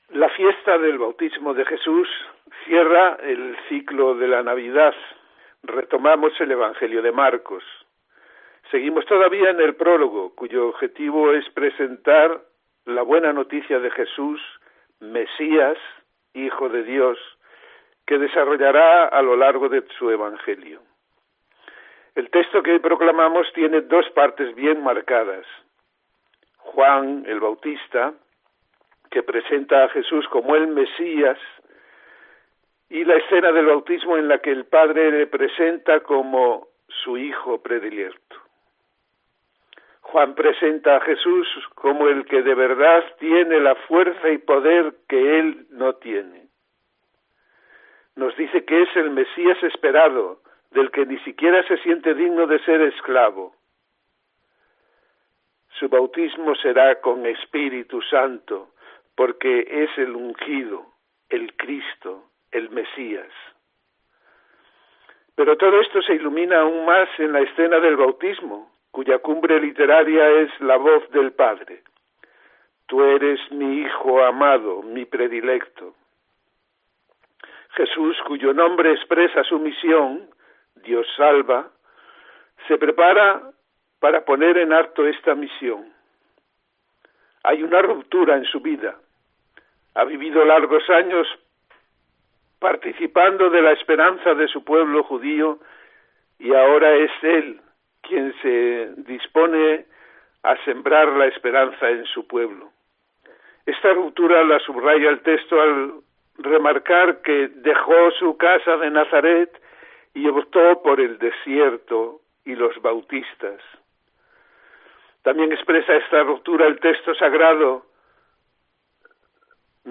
Comentario del Evangelio de este domingo, 10 de enero de 2021